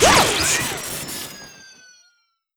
girl_charge_2.wav